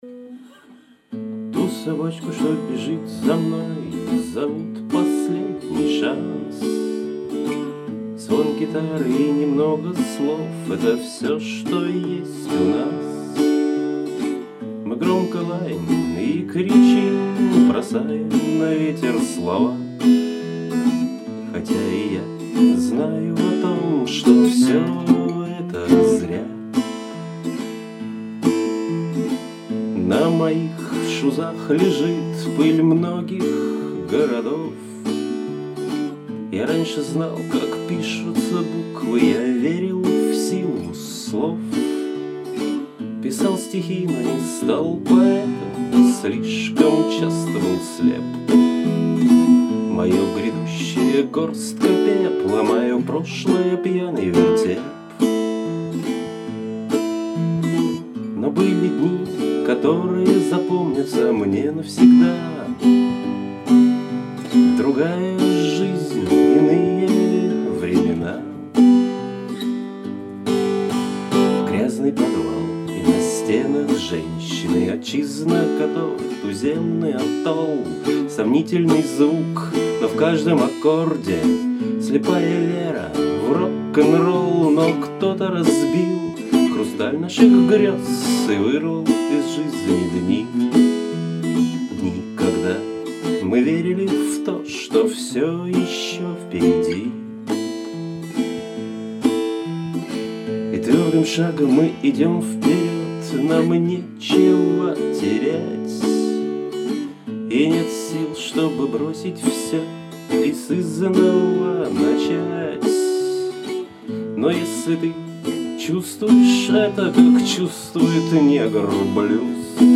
Бороды не слышно, молодо звучишь.